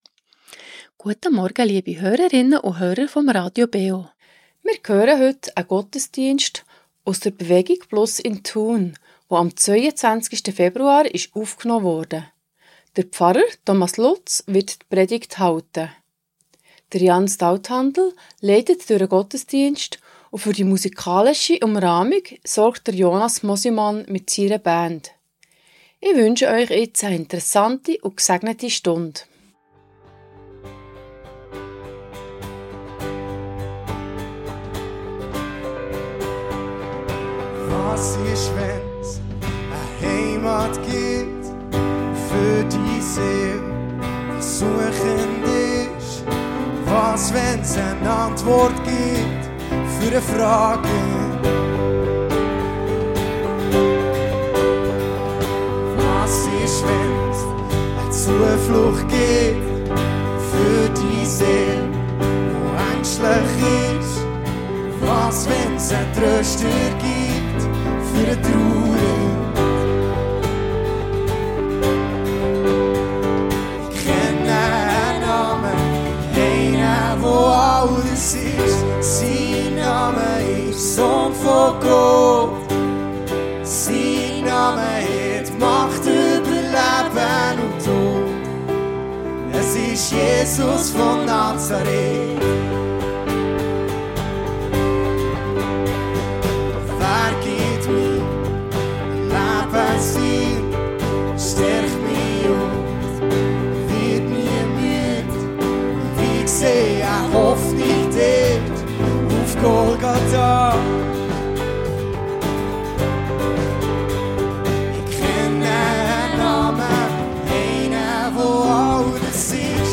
BewegungPlus Thun ~ Gottesdienst auf Radio BeO Podcast